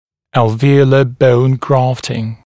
[ˌælvɪ’əulə bəun ‘grɑːftɪŋ][ˌэлви’оулэ боун ‘гра:фтин]пересадка костной ткани на альвеолярный отросток